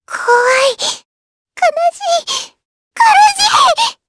Lavril-Vox_Skill7_jp.wav